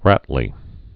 (rătl-ē)